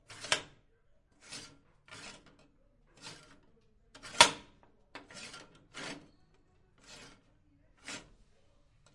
乌干达 " 金属厚重的地牢监狱门舱门打开关闭滑动吱吱作响各种int视角的offmic +bg声音Logandan Ho
描述：金属重地牢监狱门舱口打开关闭幻灯片吱吱声各种int观点关于offmic + bg声音Logandan hostel kitchen.wav